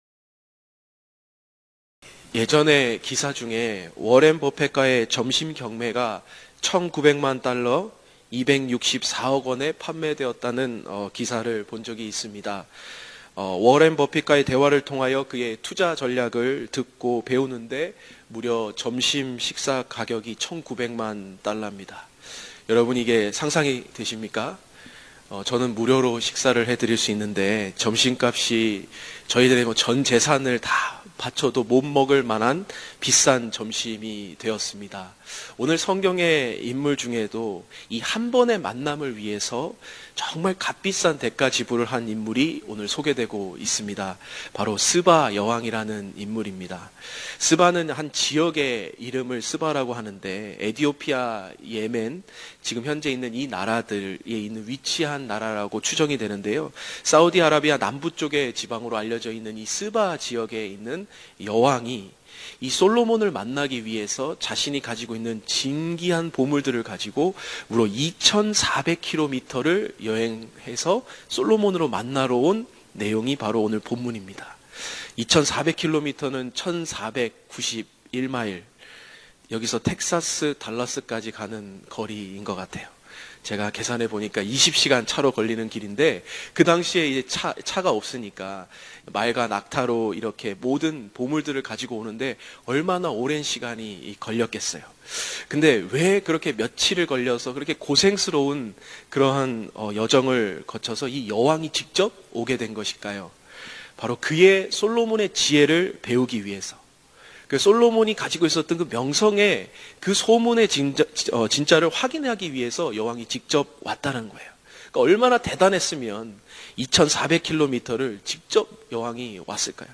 예배: 평일 새벽